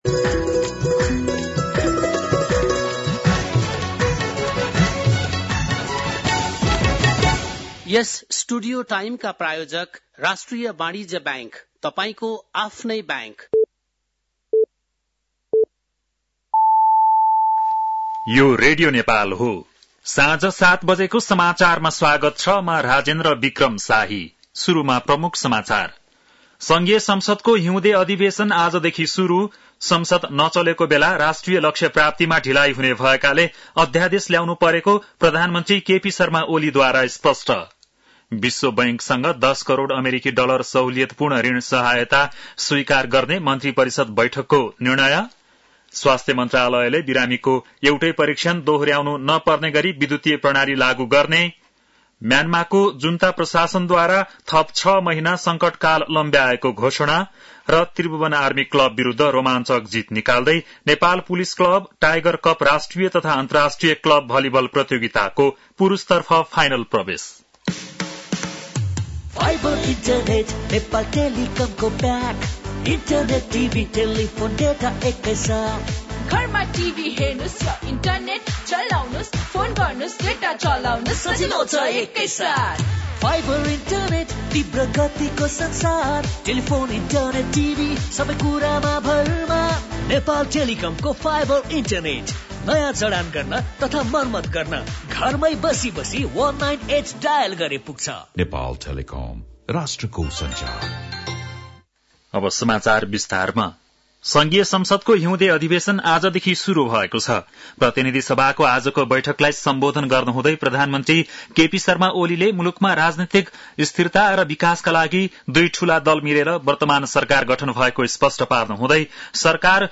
बेलुकी ७ बजेको नेपाली समाचार : १९ माघ , २०८१